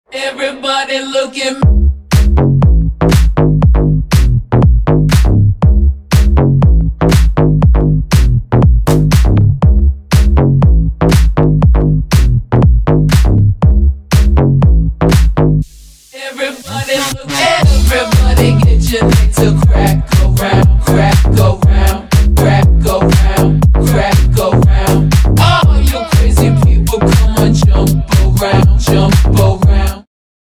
• Качество: 320, Stereo
ритмичные
женский вокал
диско
dance
club
качающие
Bass